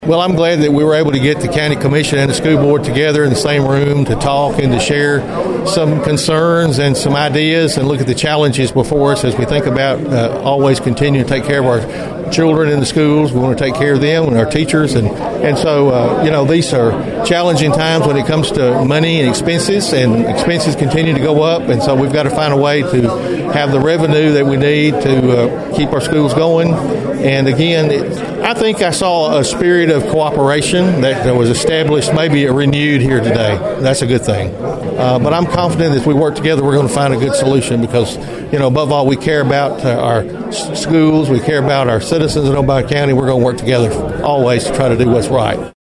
Following the nearly two hour meeting, County Mayor Steve Carr said he heard positive debate on the issue.(AUDIO)